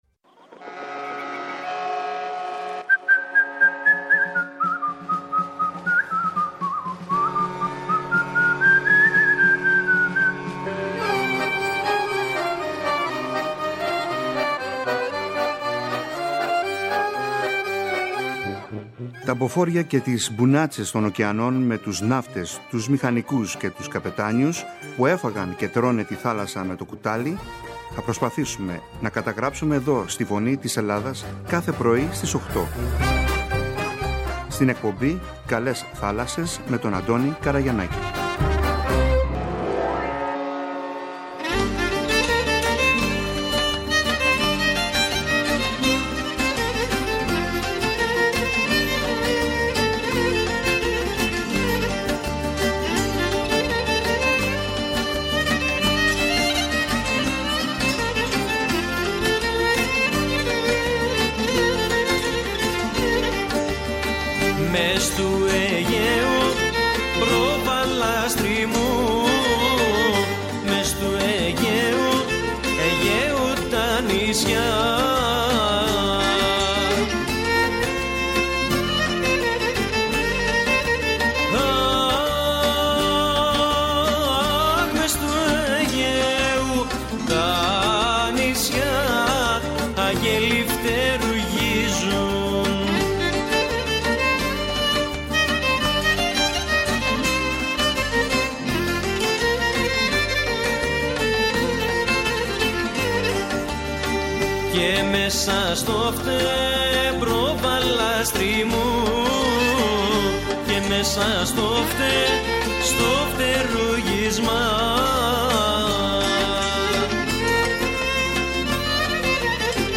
Είναι πιο πολύ ανθρωποκεντρική εκπομπή για τον ναυτικό, με τα θέματα του, τη ναυτιλία, τραγούδια, ιστορίες, συναισθήματα, σκέψεις, και άλλα πολλά όπως π.χ η γυναίκα εργαζόμενη στη ναυτιλία, η γυναίκα ναυτικού, είδη καραβιών, ιστορίες ναυτικών οικογενειών, ιστορίες ναυτικών, επικοινωνία μέσω του ραδιοφώνου, ναυτικές ορολογίες, τραγούδια, ποίηση, πεζογραφία για τη θάλασσα, εξαρτήματα του πλοίου, ήδη καραβιών ναυτικά επαγγέλματα κλπ κλπ Ήδη έχουν ανταποκριθεί αρκετοί, παλιοί και εν ενεργεία καπετάνιοι και μίλησαν με μεγάλη χαρά και συγκίνηση για την ζωή – καριέρα τους στην θάλασσα και τι σημαίνει για αυτούς.